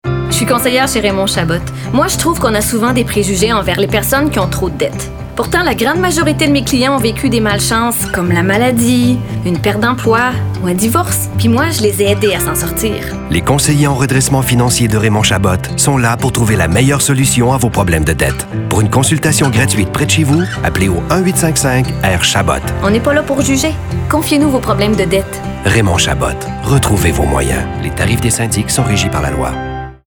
Pour Raymond Chabot, leader de cette industrie au Québec, nous avons plutôt adoptés un ton calme, respectueux et qui invite à la confidence.
Radios